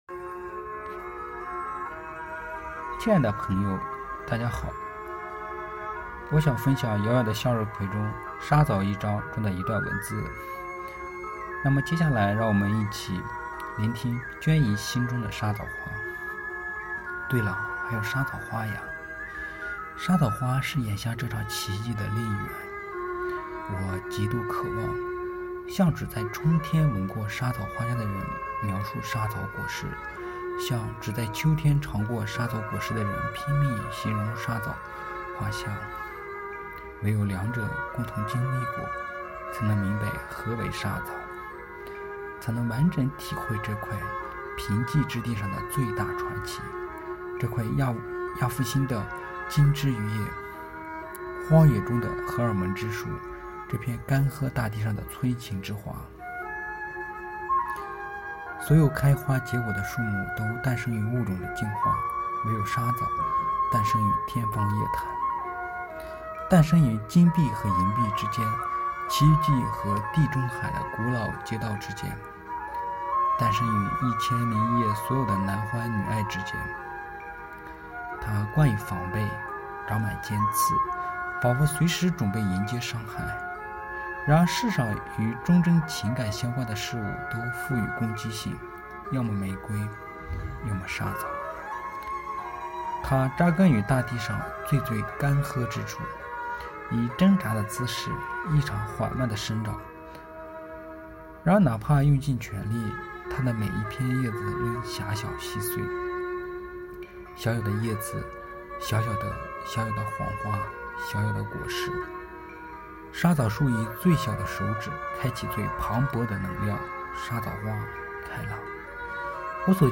今天，几位同学选取了自己喜欢的段落进行朗诵，与大家分享他们在《遥远的向日葵地》中看到的风景。